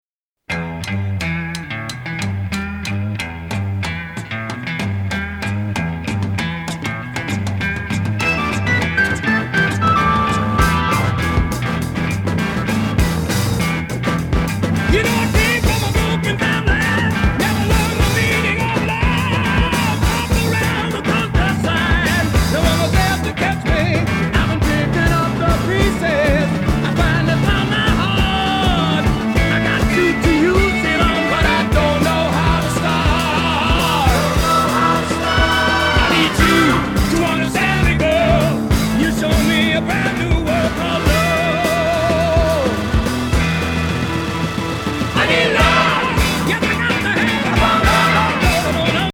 強烈に脳天を突くBLUES GROOVE!!